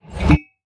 fill_dragonbreath1.ogg